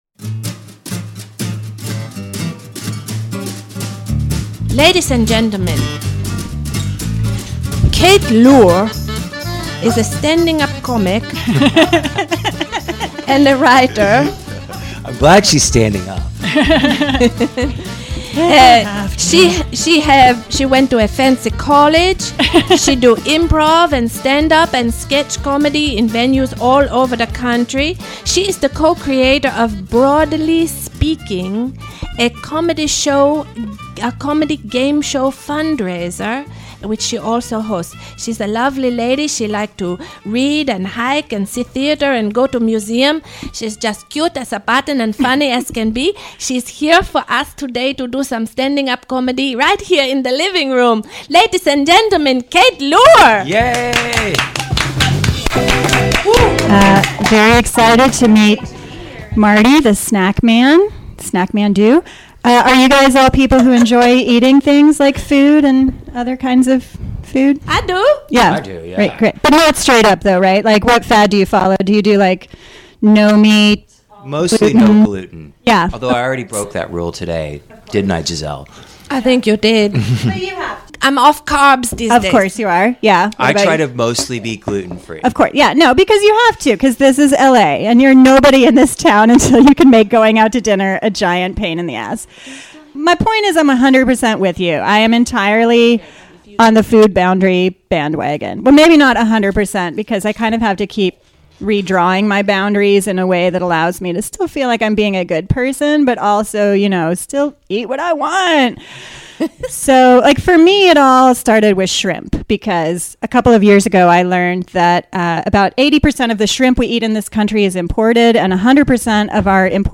Comedienne
Living Room Comedy Show